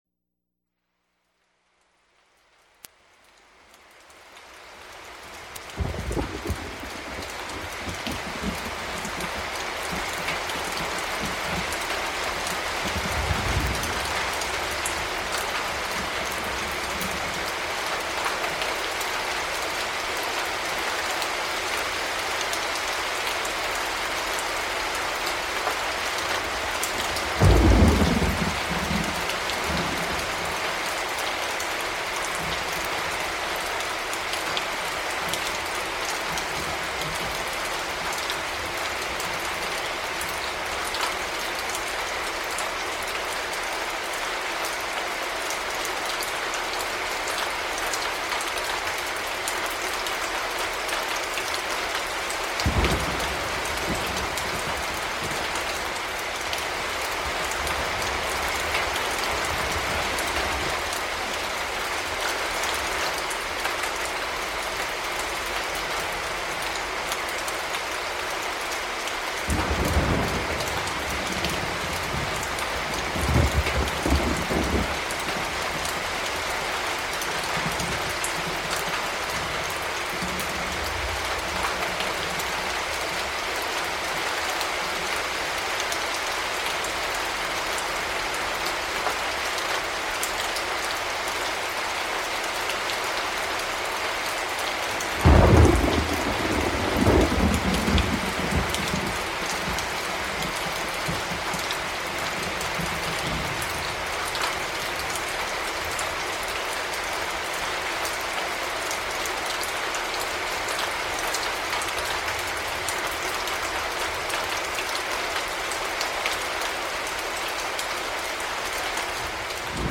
Звуки природы звуки скачать, слушать онлайн ✔в хорошем качестве